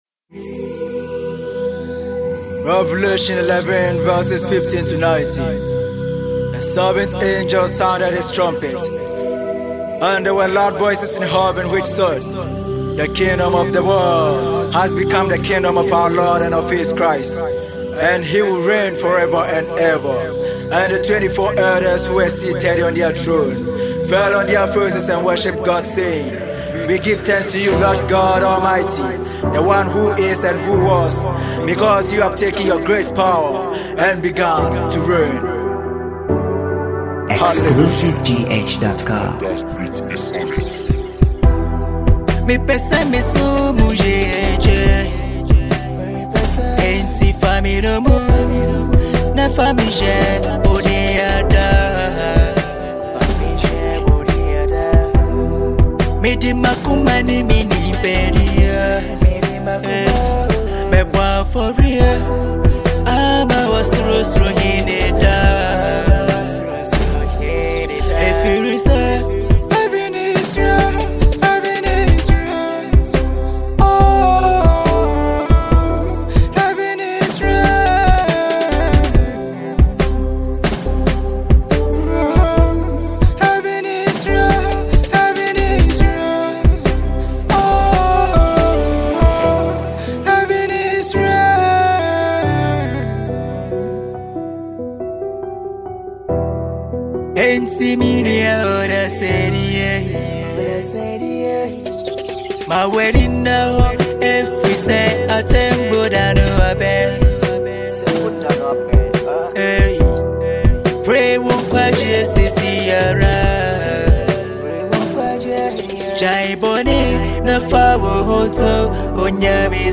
RnB gospel